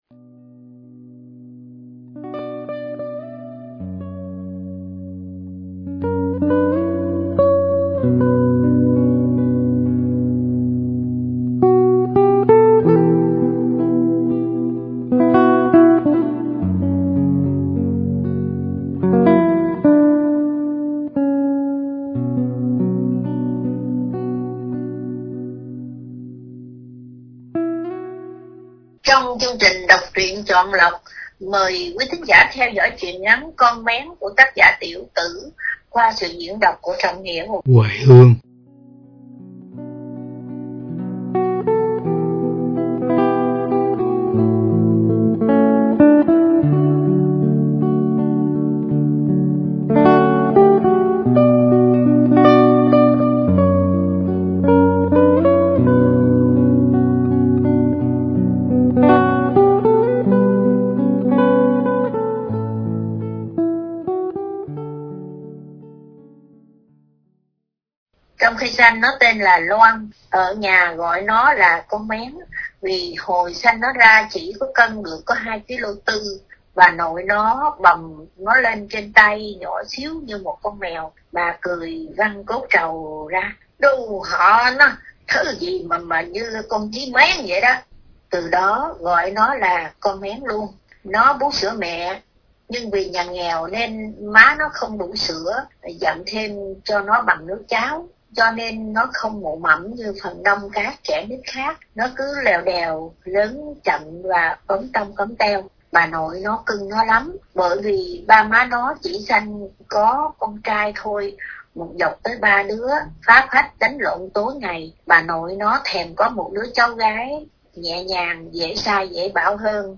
Đọc Truyện Chọn Lọc – Truyện Ngắn “Con Mén”- Tiểu Tử – Radio Tiếng Nước Tôi San Diego